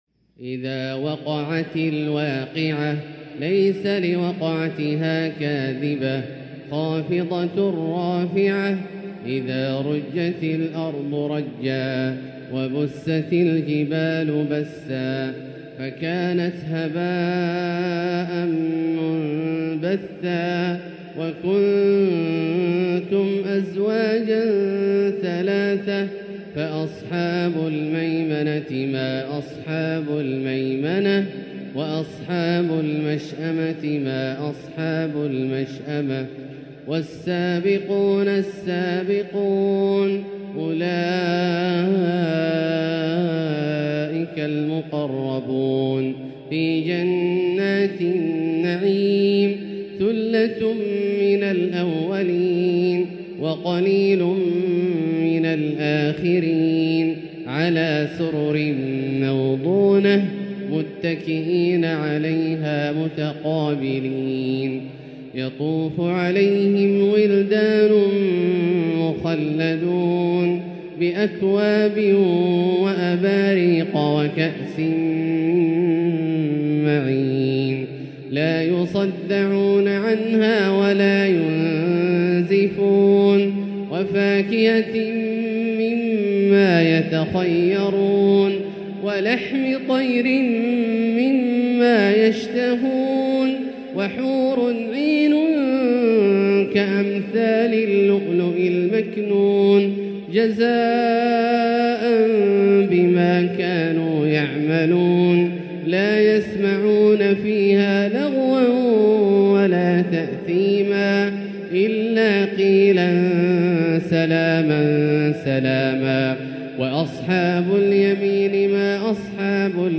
تلاوة أخشعت الوجدان لـ سورة الواقعة كاملة للشيخ د. عبدالله الجهني من المسجد الحرام | Surat Al-Waqiah > تصوير مرئي للسور الكاملة من المسجد الحرام 🕋 > المزيد - تلاوات عبدالله الجهني